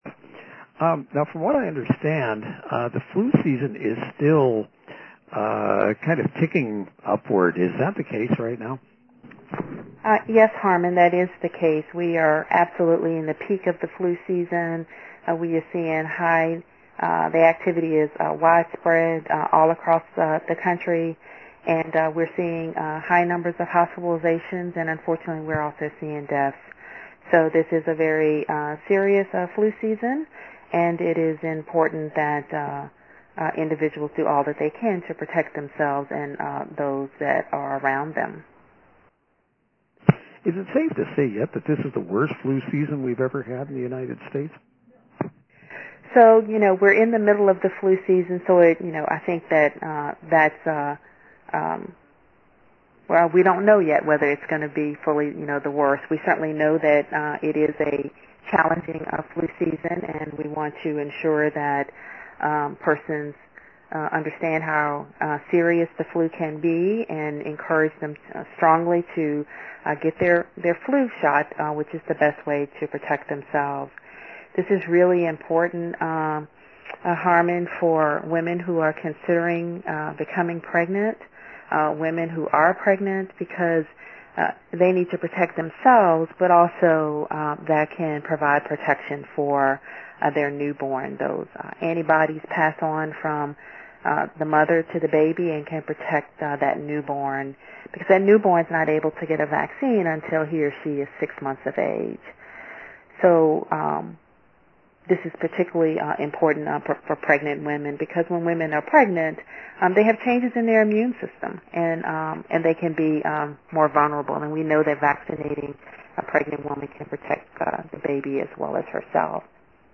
Radio interviews:
10:10 am: five minute taped interview on Metro Radio Networks (Washington State & National).